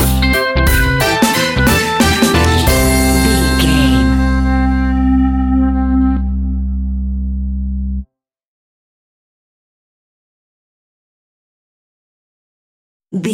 Take me back to the old skool retro seventies reggae sounds!
Ionian/Major
dub
reggae instrumentals
laid back
chilled
off beat
drums
skank guitar
hammond organ
transistor guitar
percussion
horns